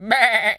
pgs/Assets/Audio/Animal_Impersonations/goat_baa_stressed_hurt_06.wav at master
goat_baa_stressed_hurt_06.wav